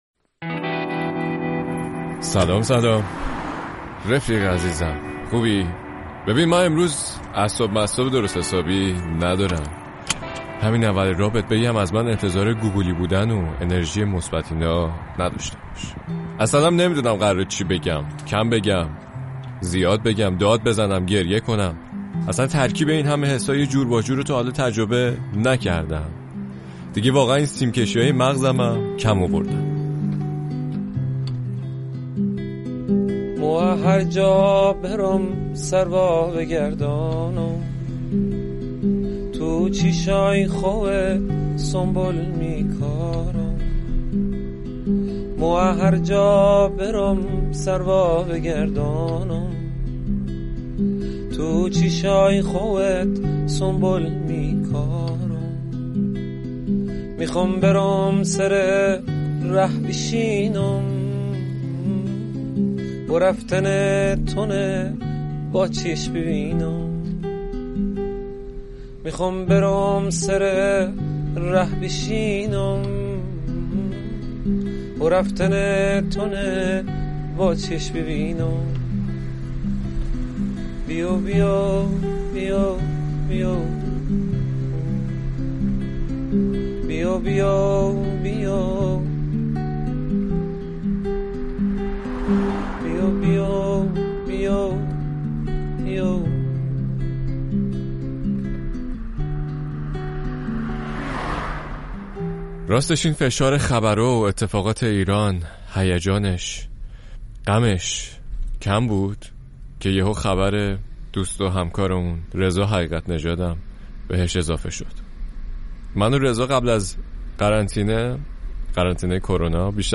پادکست موسیقی